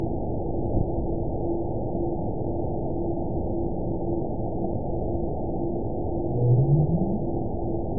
event 922851 date 04/19/25 time 22:56:39 GMT (1 month, 3 weeks ago) score 9.63 location TSS-AB02 detected by nrw target species NRW annotations +NRW Spectrogram: Frequency (kHz) vs. Time (s) audio not available .wav